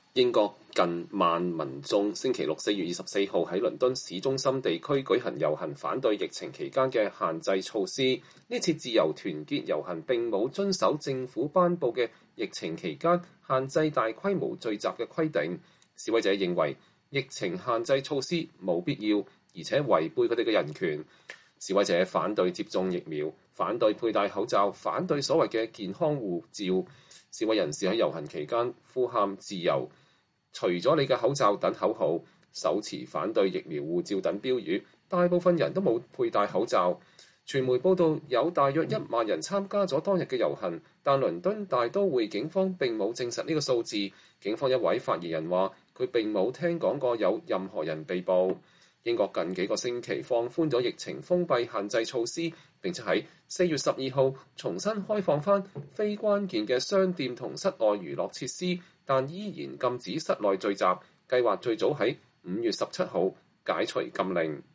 示威人士在遊行期間呼喊“自由，”“摘掉你的口罩”等口號，手持反對疫苗護照等標語。